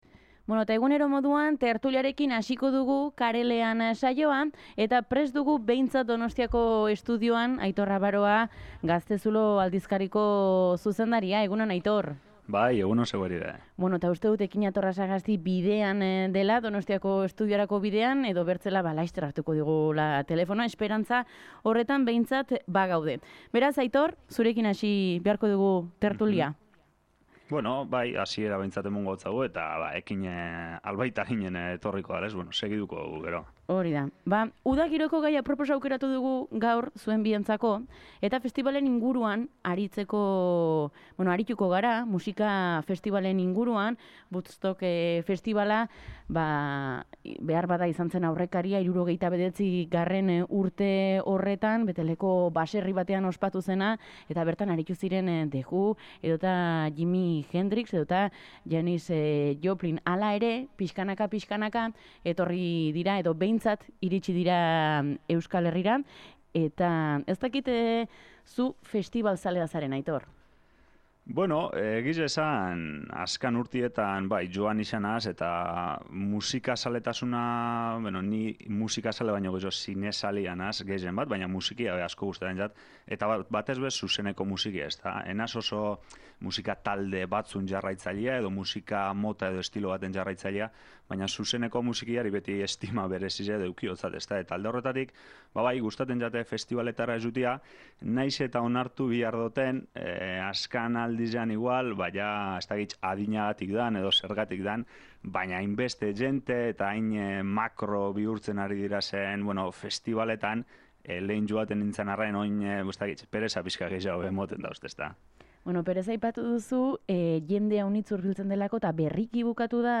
Tertulia Karelean saioan